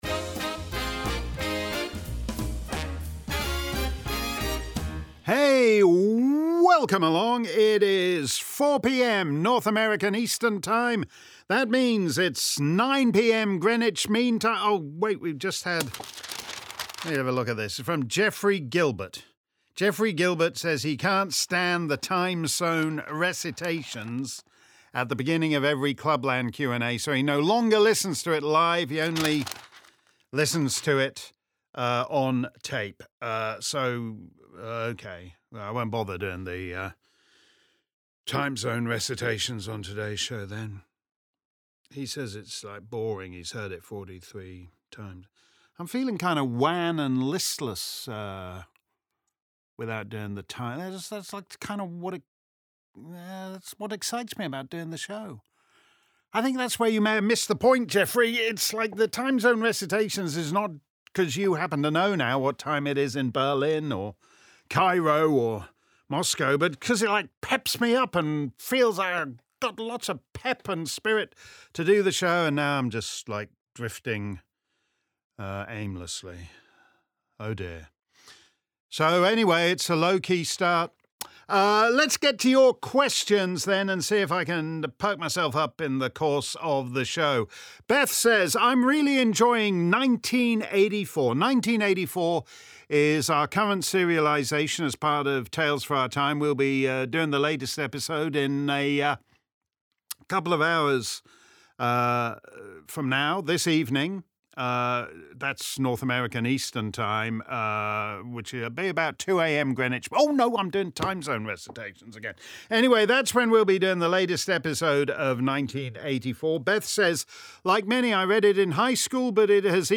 If you missed our livestream Clubland Q&A on Friday afternoon, here's the action replay.